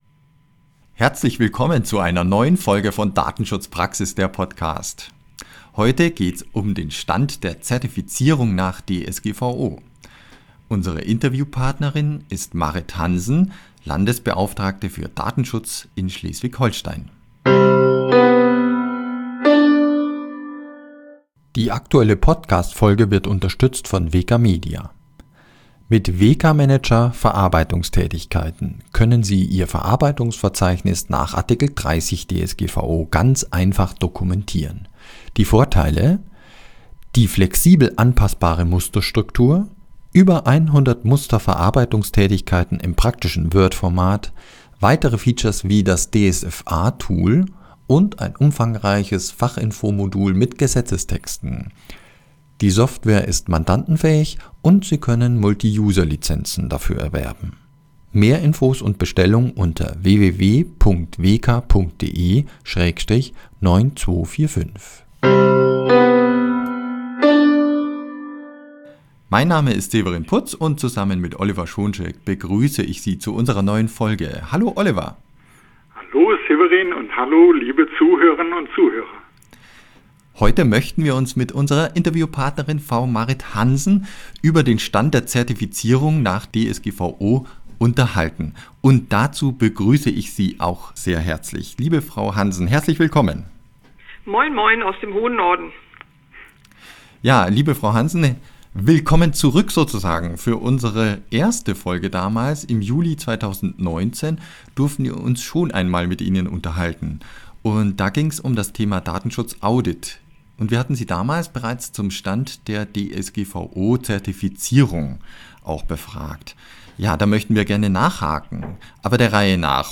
Im Zusammenhang mit dem Inkrafttreten des Hinweisgeberschutzgesetzes stellen sich eine Vielzahl von datenschutzrechtlichen Fragen. Wir haben Prof. Dr. Tobias Keber, Landesbeauftragter für den Datenschutz und die Informationsfreiheit Baden-Württemberg (LfDI), dazu befragt.